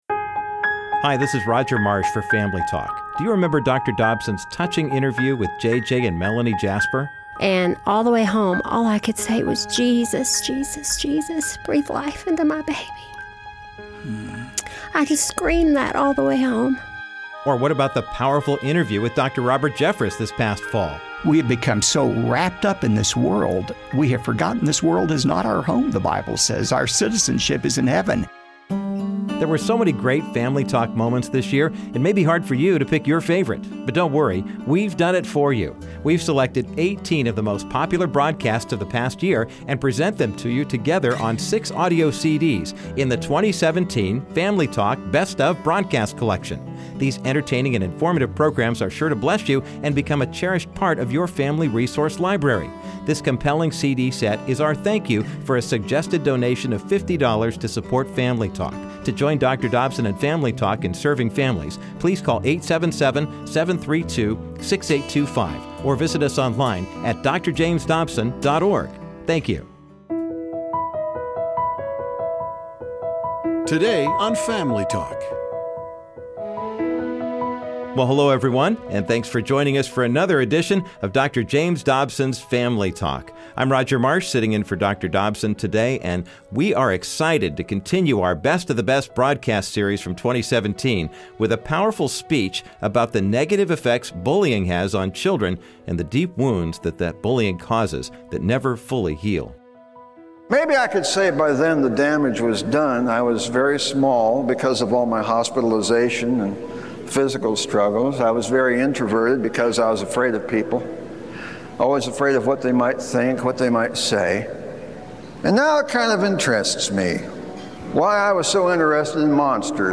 Today, Family Talk is continuing its 2017 Best of Broadcast series with another well-liked program. On this episode, you will hear from author Frank Peretti, who spoke to a youth conference about how cruel bullying plagued his childhood and created deep emotional scars that have stuck with him his entire life.